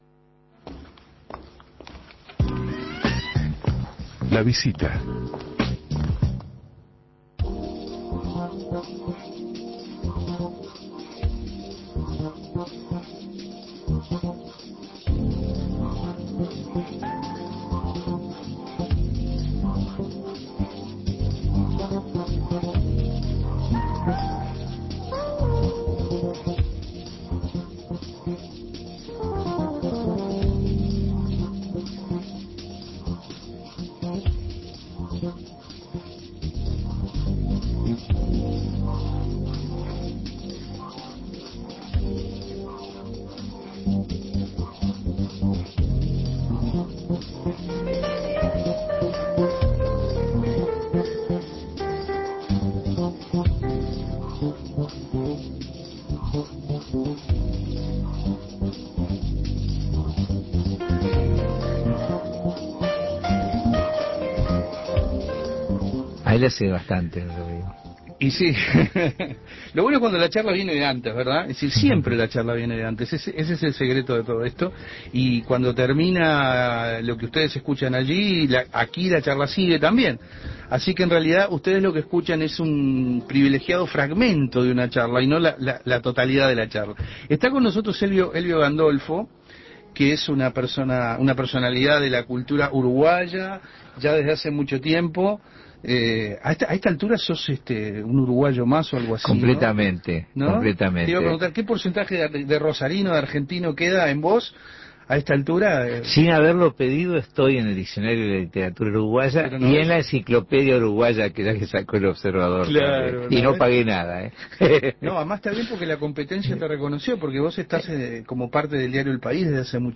El periodista cultural Elvio Gandolfo presentó su último libro de cuentos: "Mujeres". En una entrevista cálida y llena de humor, el escritor explicó sobre esta nueva obra.